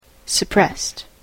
/sʌˈprɛst(米国英語), sʌˈprest(英国英語)/